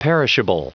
Prononciation du mot perishable en anglais (fichier audio)
Prononciation du mot : perishable